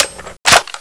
sg550_clipin.wav